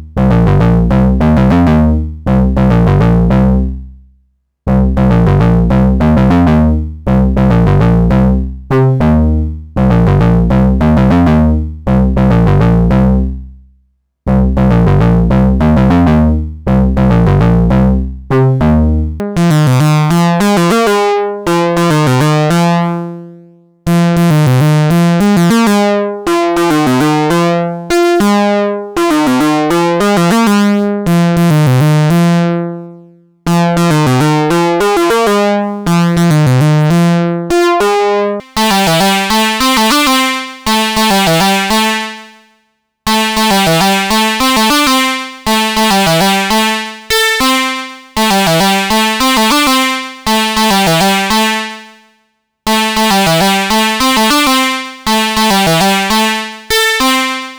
this is compact but hi-quality feedforward vca compressor.
tube vca works as compressor modulated from cv2 out of microcompressor. filter - mutant vactrol vcf. no effects.
tube_compression.wav